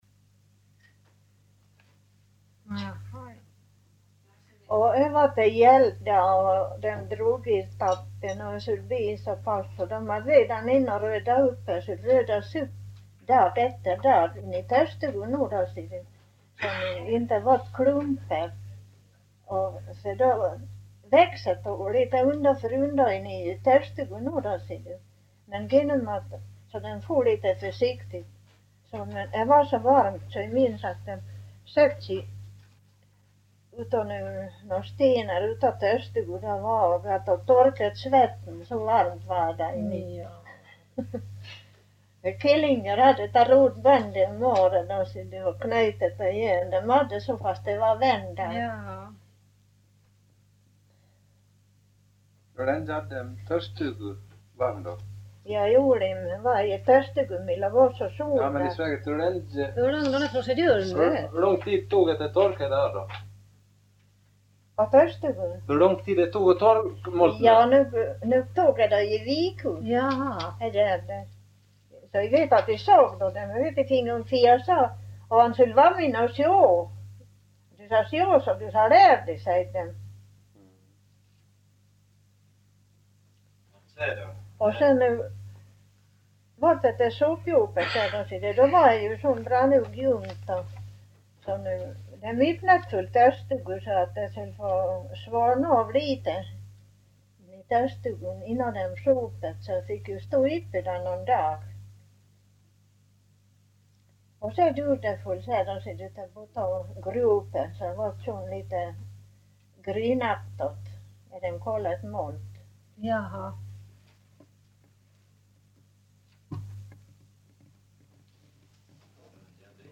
Intervjuas av ”Soldmålsklubben” 10 dec 1972.